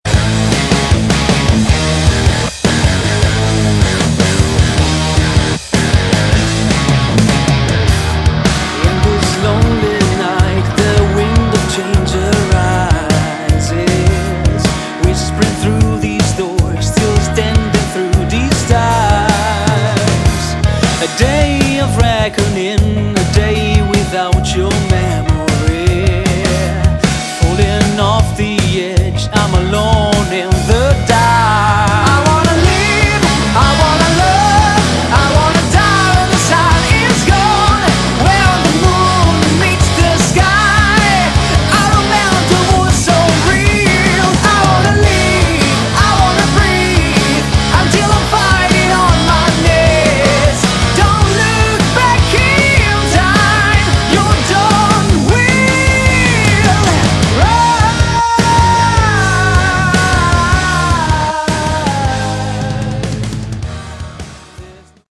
Category: Modern Hard Rock
vocals, guitars
bass
drums